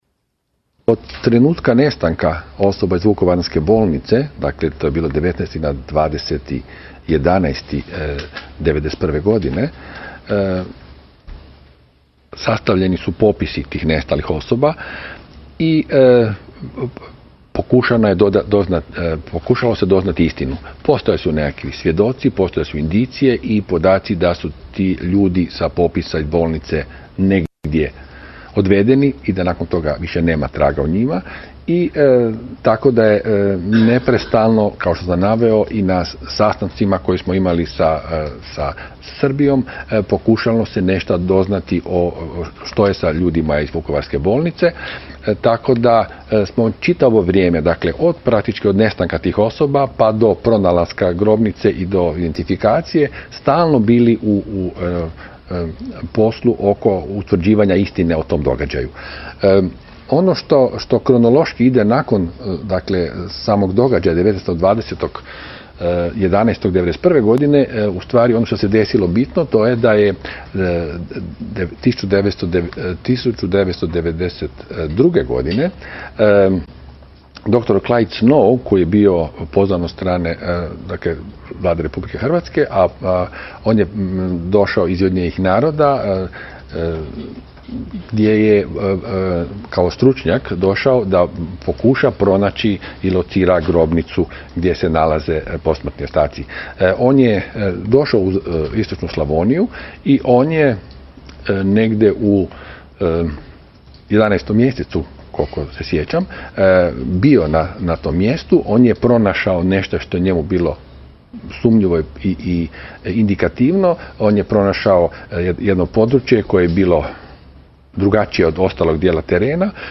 Suđenje Hadžiću: Profesor sudske medicine o ubijanju na Ovčari